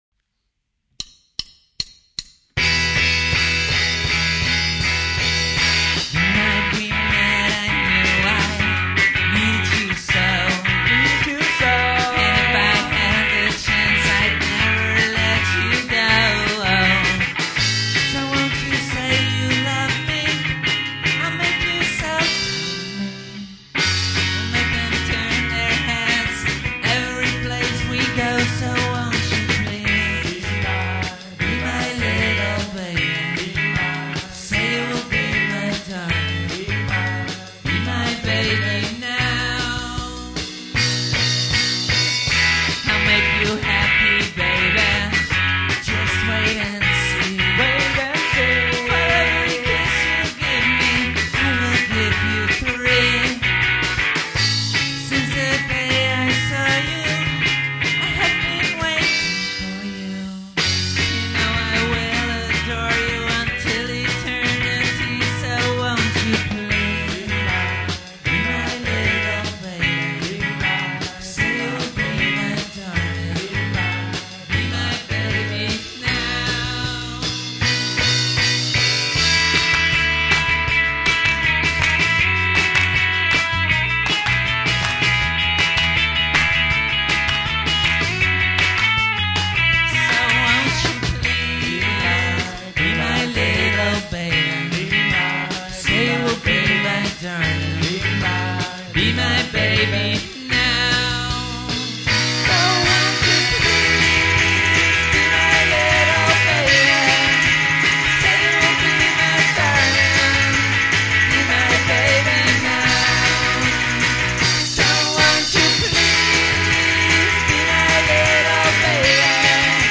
cover
backing vocals